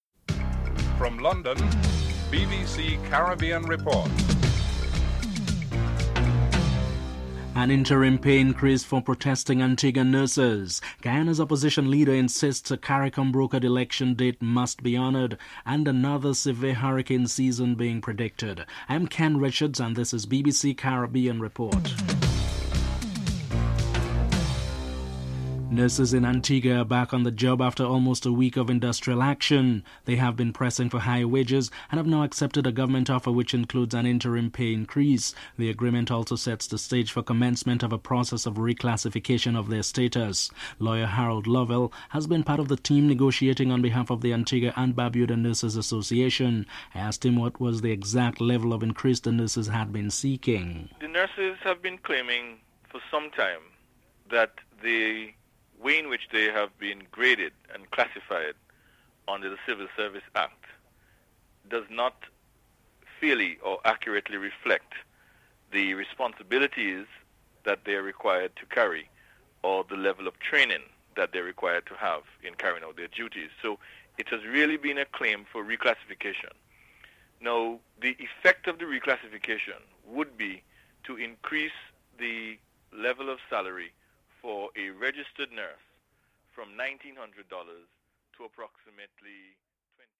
1. Headlines: (00:00-00:24)